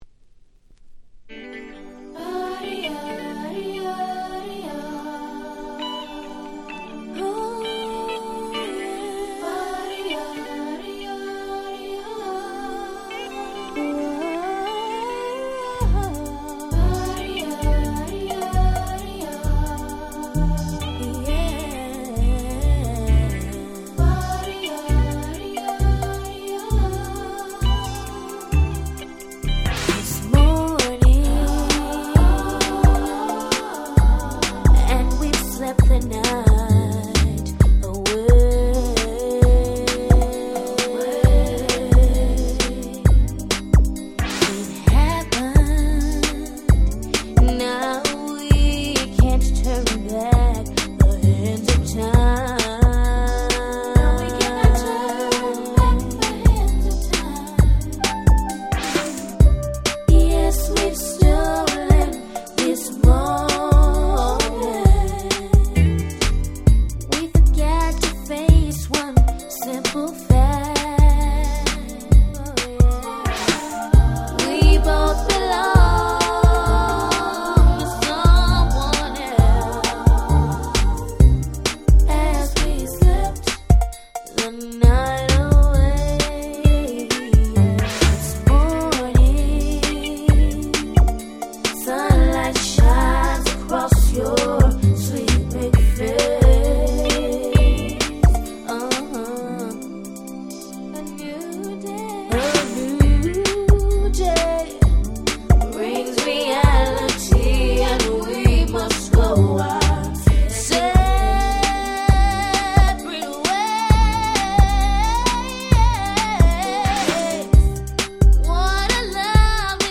97' Miami Bass / R&B Classics !!
90's マイアミベース